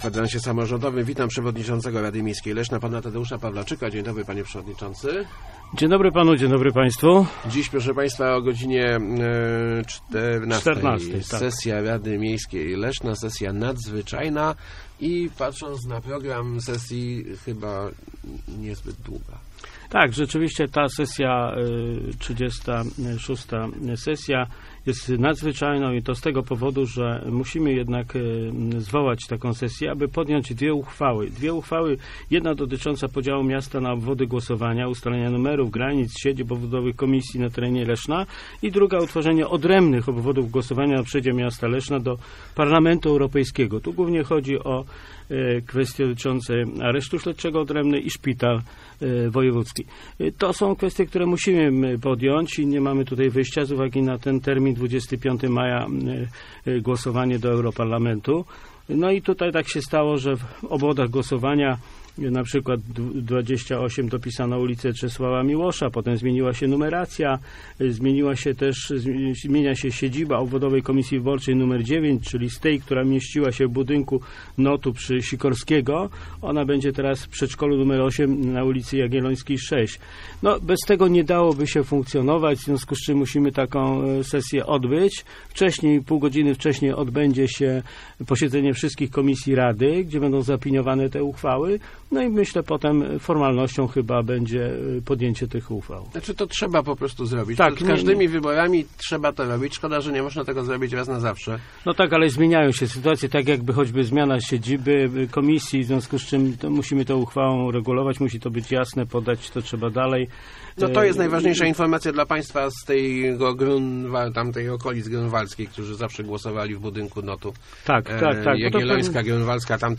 Gościem Kwadransa był Tadeusz Pawlaczyk, przewodniczący RML.